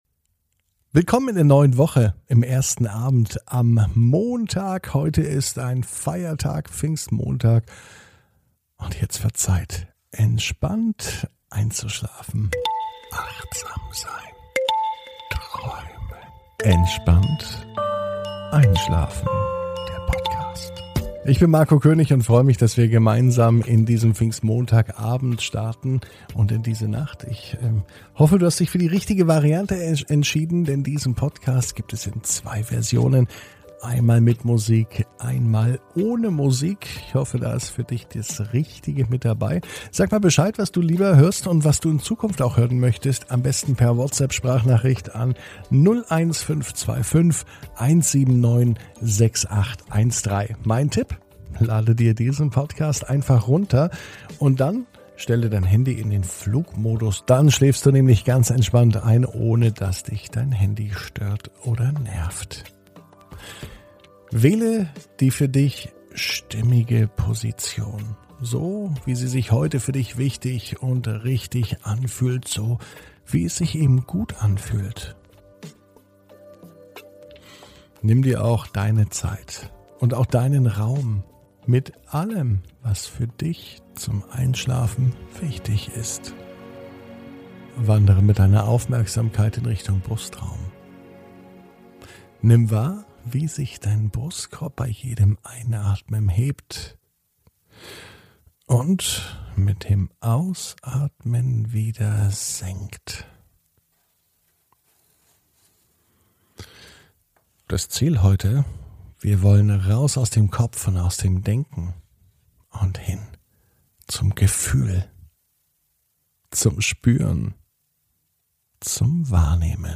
(Ohne Musik) Entspannt einschlafen am Montag, 24.05.21